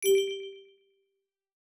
minimal_box.wav